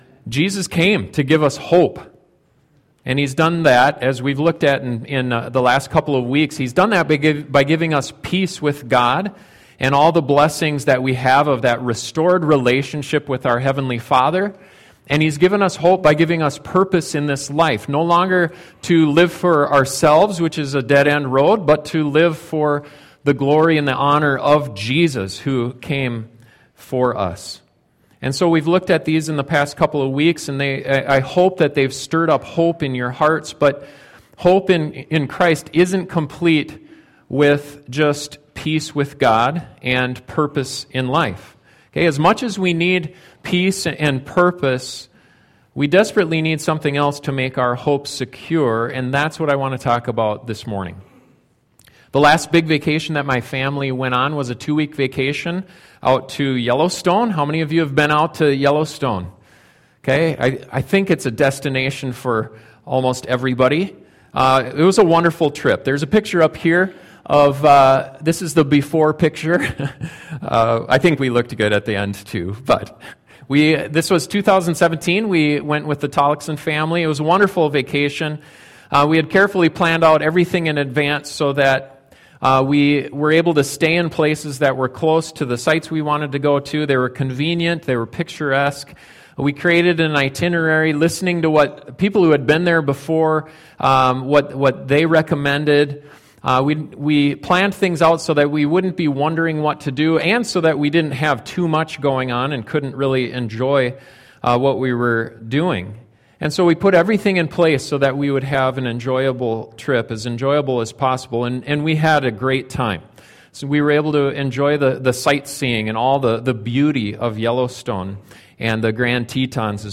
Hope for Eternal Life - East Lincoln Alliance Church